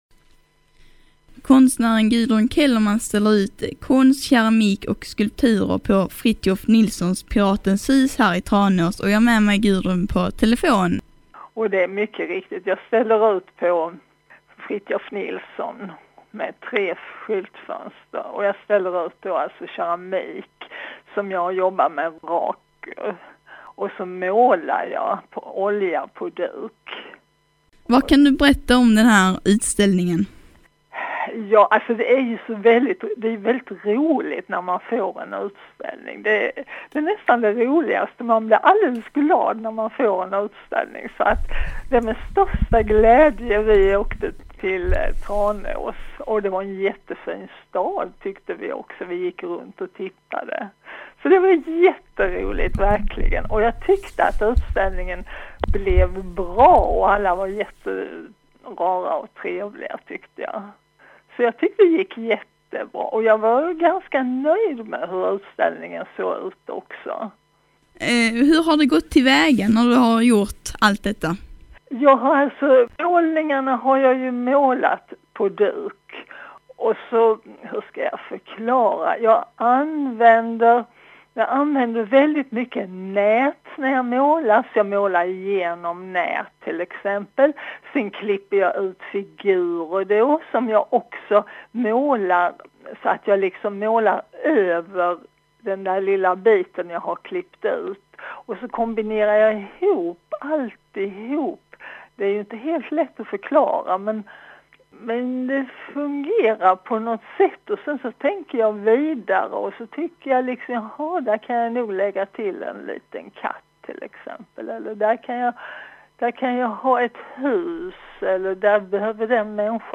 OBS att intervjun fortsätter efter avbrott för sång. Radiointervju via telefon den 11:e april 2016 Välkommen till min ateljé!